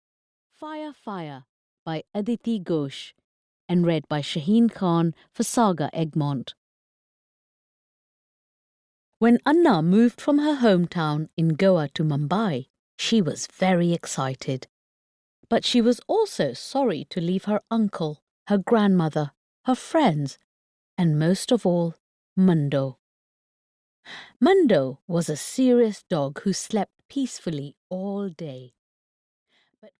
Fire! Fire! (EN) audiokniha
Ukázka z knihy